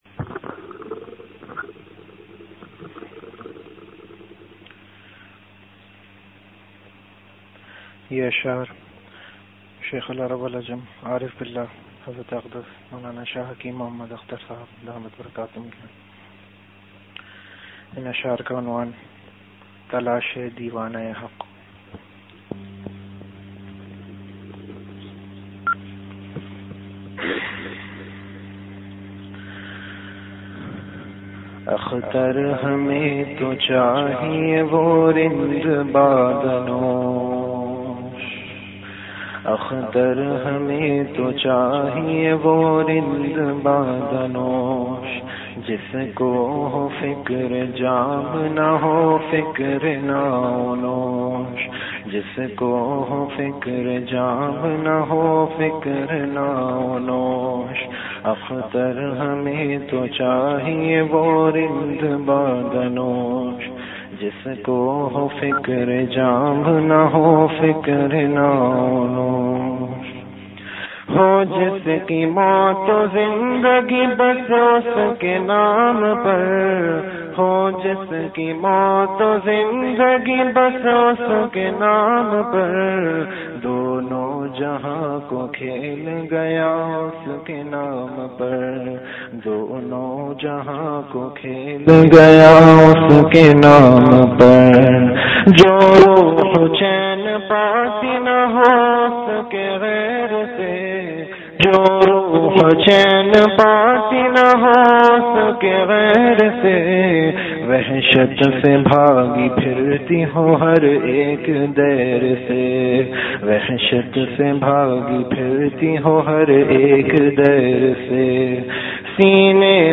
Category Majlis-e-Zikr
Venue Home Event / Time After Isha Prayer